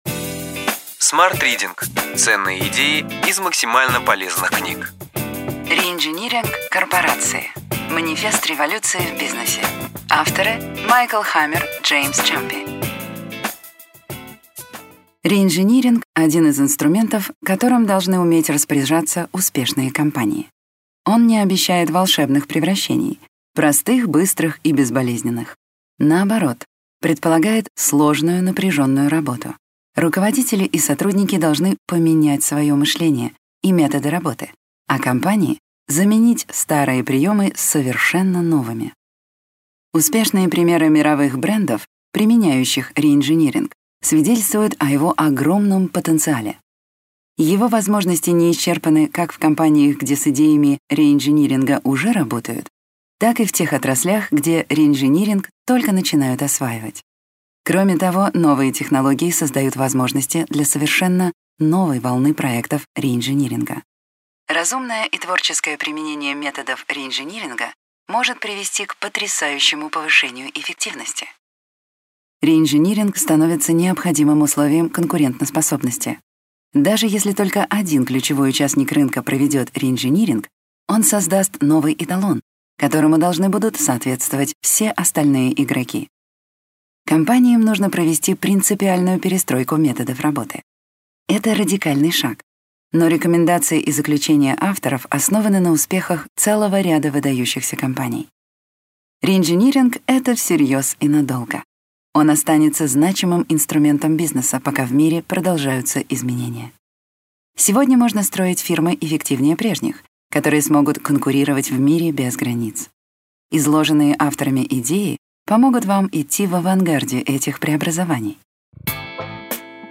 Аудиокнига Ключевые идеи книги: Реинжиниринг корпорации. Манифест революции в бизнесе.